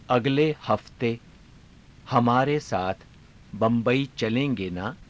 ゆっくり ふつう